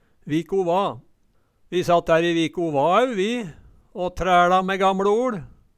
viko va - Numedalsmål (en-US)
Hør på dette ordet Ordklasse: Uttrykk Kategori: Uttrykk Attende til søk